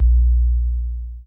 MB Kick (39).WAV